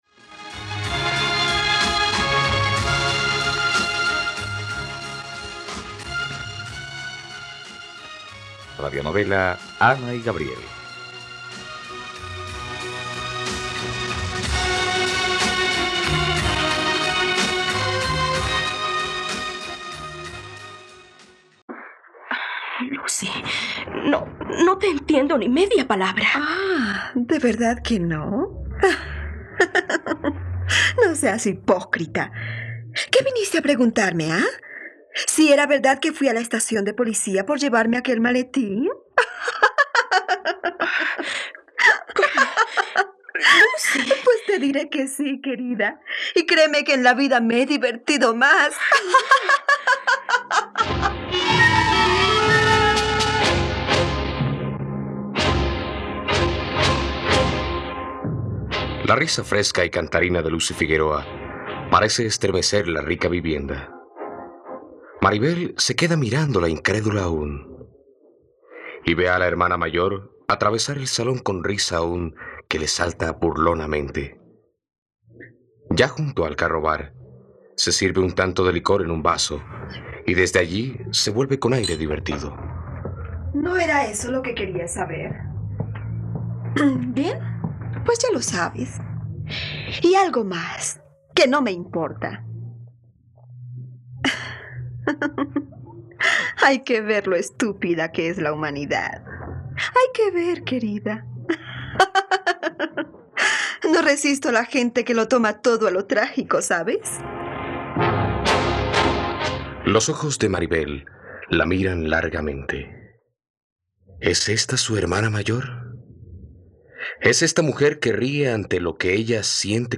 ..Radionovela. Escucha ahora el capítulo 28 de la historia de amor de Ana y Gabriel en la plataforma de streaming de los colombianos: RTVCPlay.